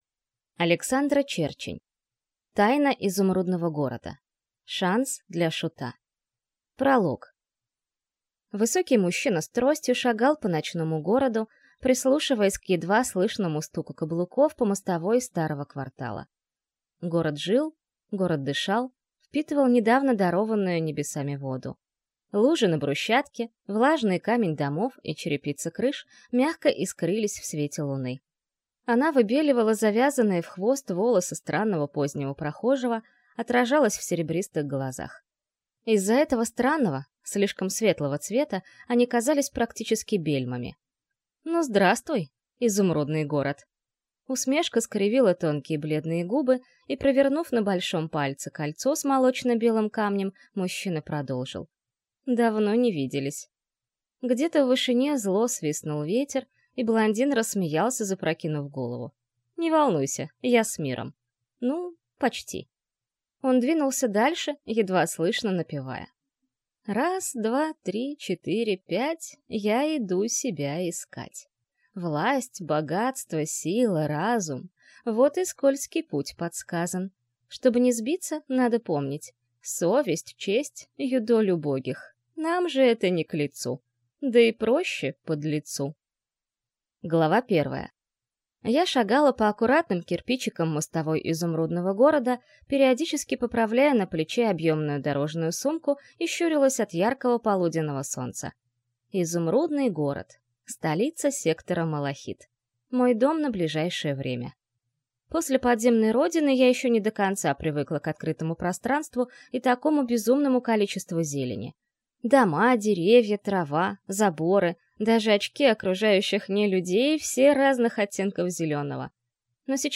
Аудиокнига Тайна Изумрудного города. Шанс для шута - купить, скачать и слушать онлайн | КнигоПоиск